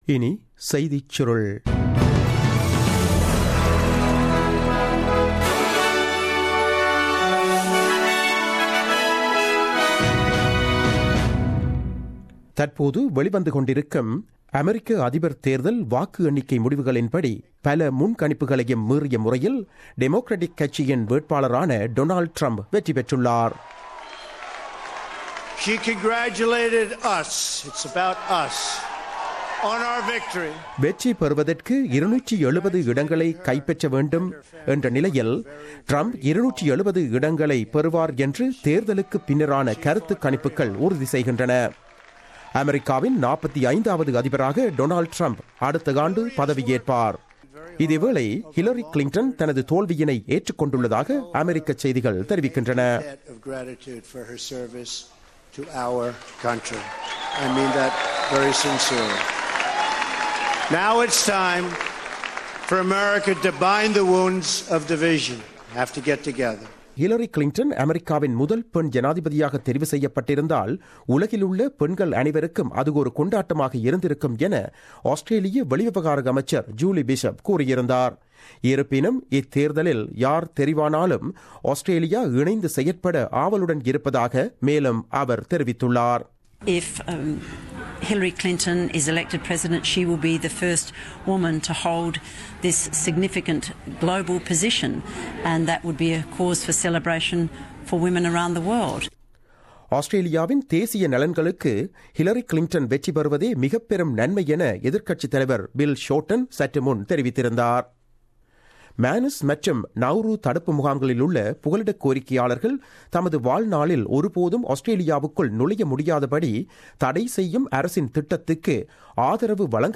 The news bulletin aired on 09 November 2016 at 8pm.